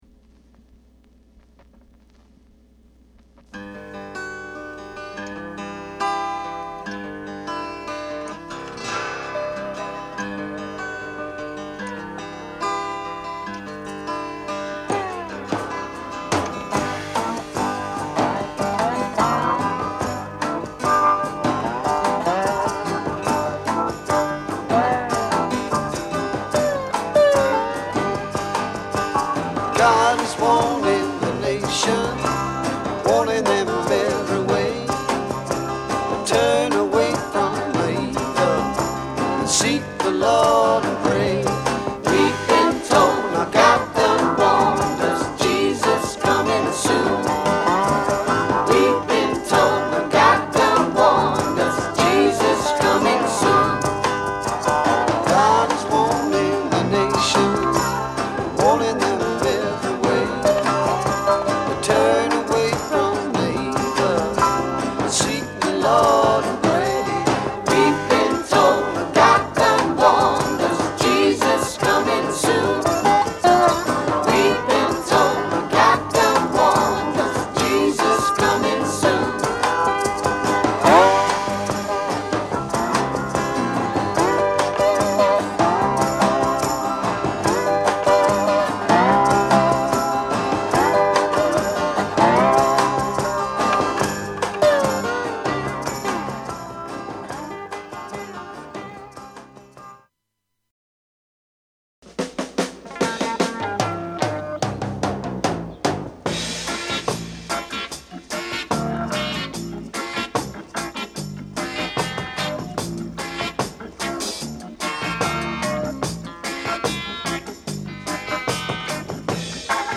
ROCK / BRITISH ROCK / BLUES
盤は薄い擦れや僅かですが音に影響がある傷がいくつかある、使用感が感じられる状態です。